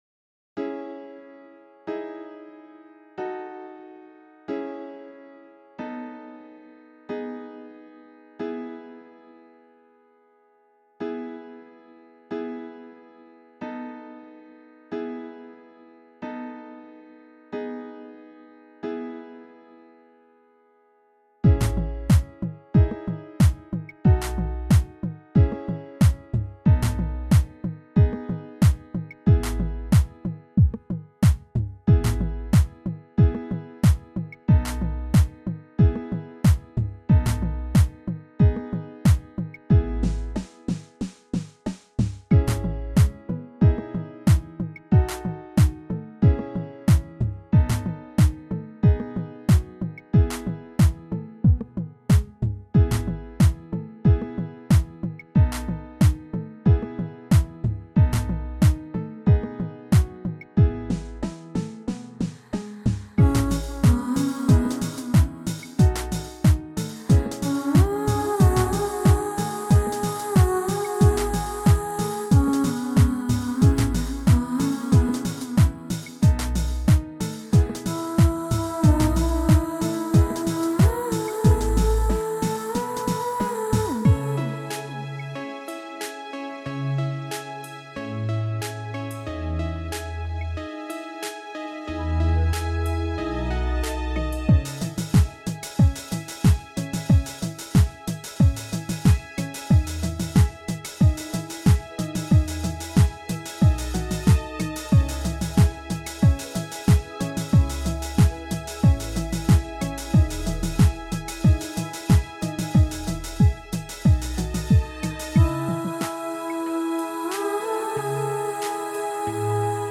un album musical composé par l’intelligence artificielle